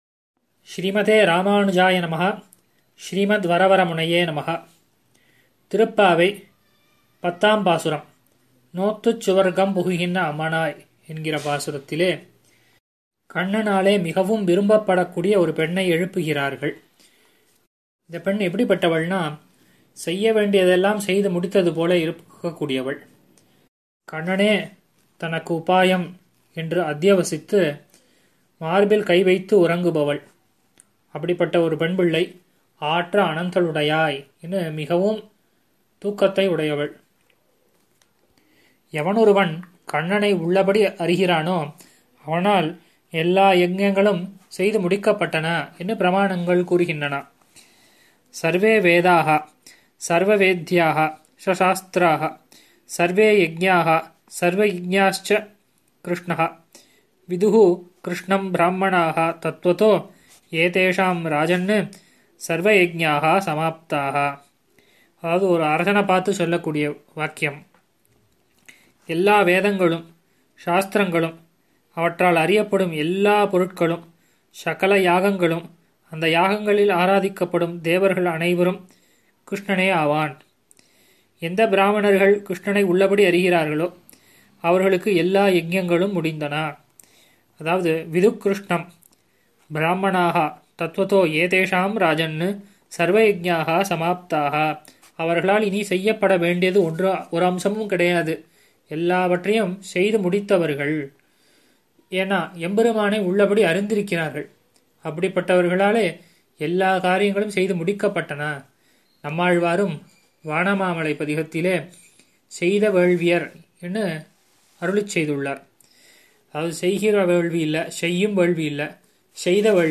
ஆறெனக்கு நின் பாதமே சரண் குழுமத்தினர் வழங்கும் சார்வரி ௵ மார்கழி ௴ மஹோத்ஸவ உபன்யாசம் –